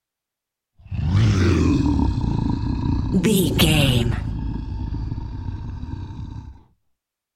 Monster angry reaction growl big creature
Sound Effects
scary
ominous
angry